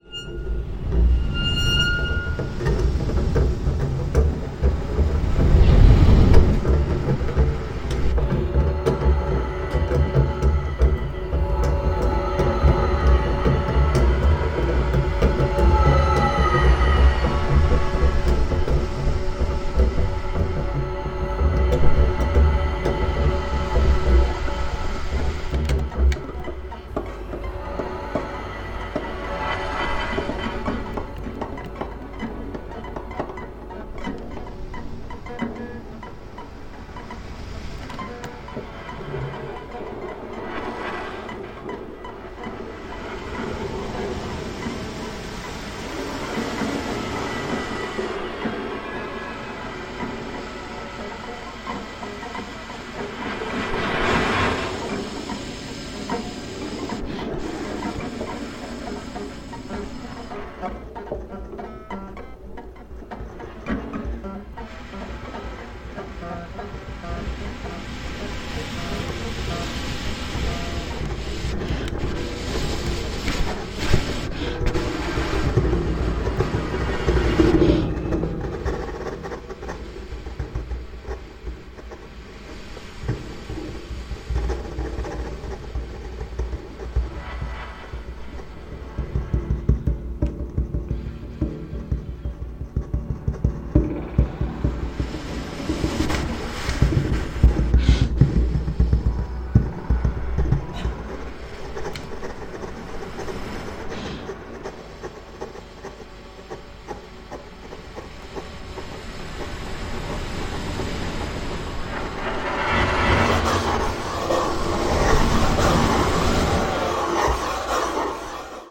演奏者それぞれがクラヴィコードを使った凄まじくシリアスな鉱物即興&ライブエレクトロニクス作
キリキリと恐ろしい摩擦音と無骨な打撃の集積はもはや物音的な領域。
free improvised music by
monochord, clavichord, melodica
clavichord, electronics
tenor and bass recorder